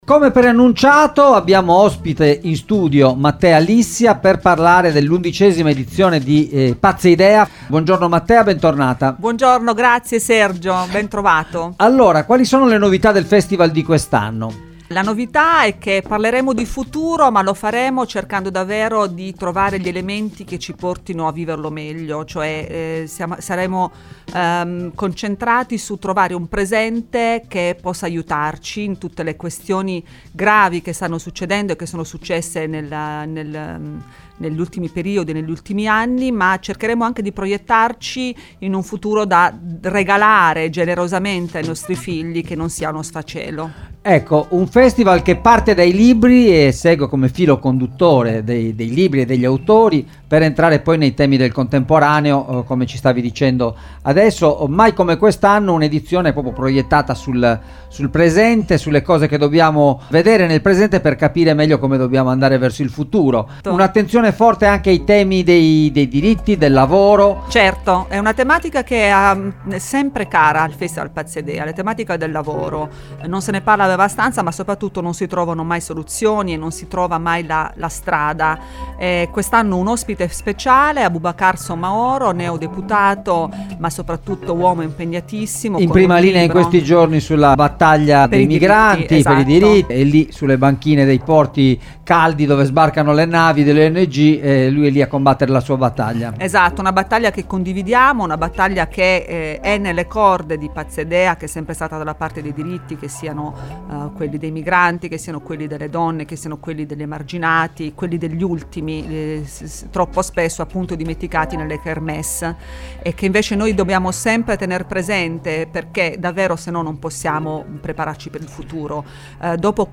Idee e riflessioni per un futuro più giusto: a Cagliari dal 25 al 27 novembre la XI edizione del festival Pazza Idea - Intervista